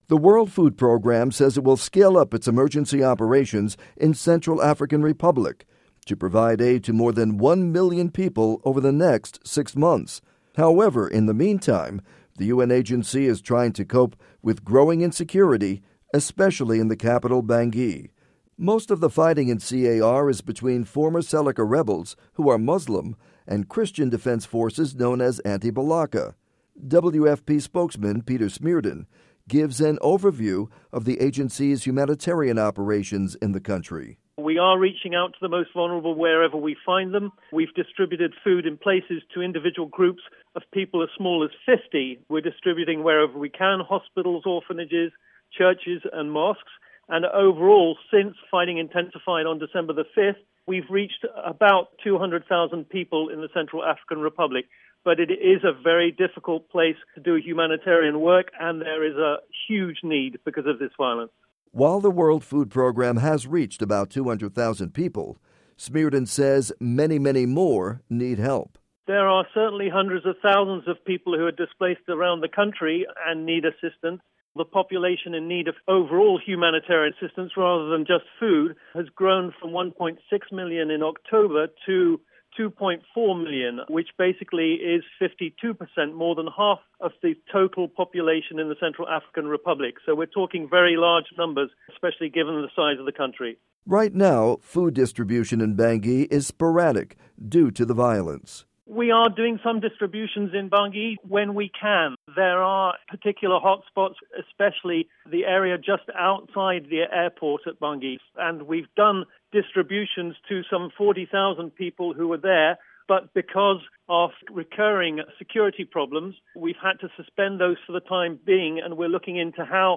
report on food aid in CAR